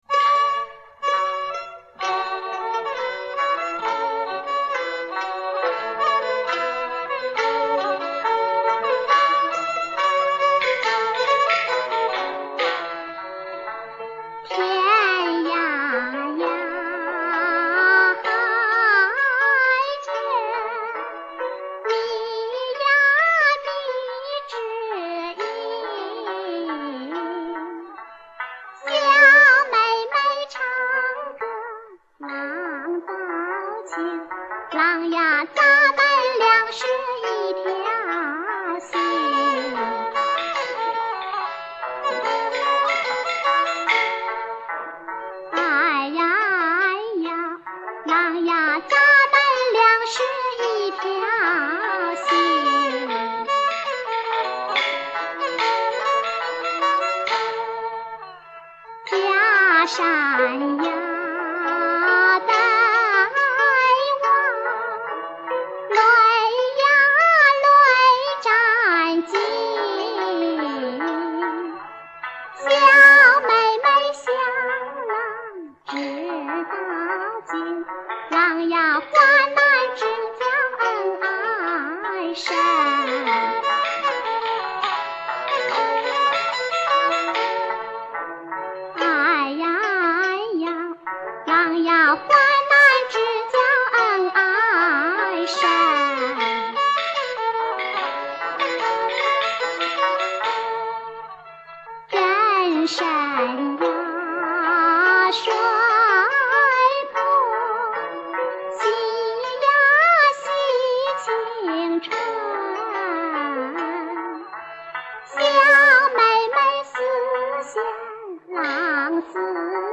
所谓逼真复活版 就是利用现代技术对老唱片进行还原 以求再现唱片原貌